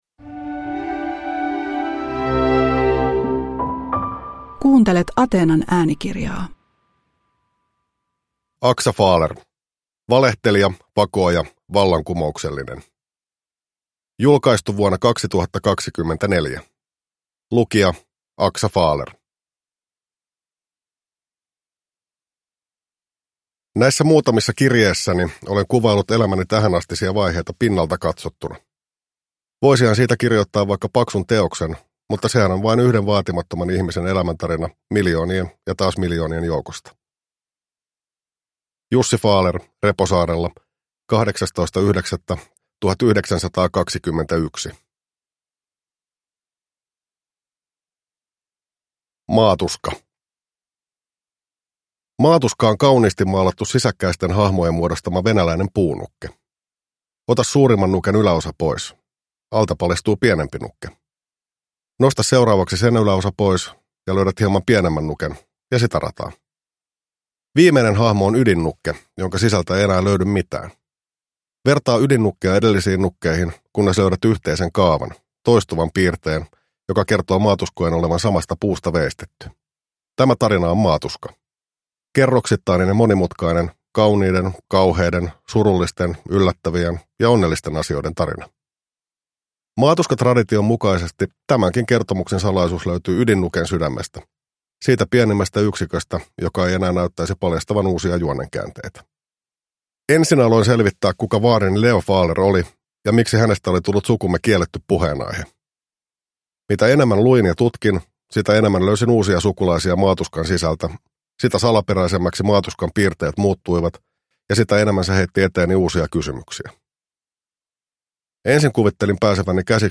Valehtelija, vakooja, vallankumouksellinen – Ljudbok